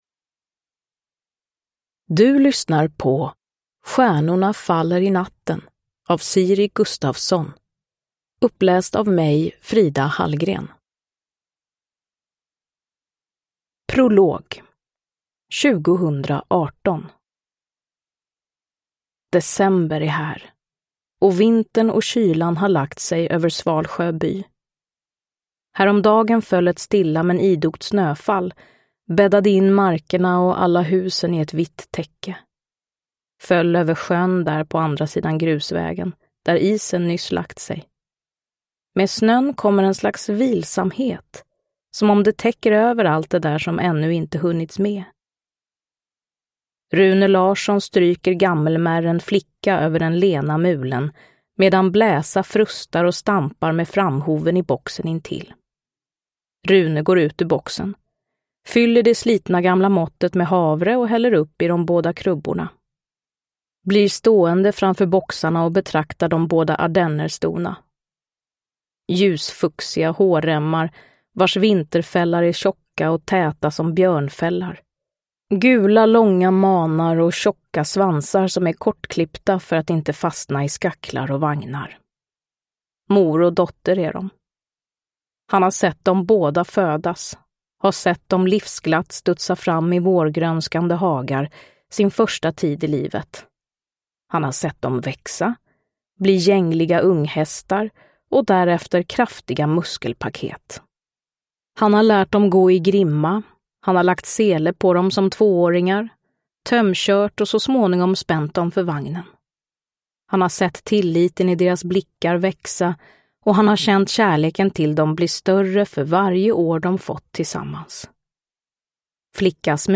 Stjärnorna faller i natten (ljudbok) av Siri Gustafsson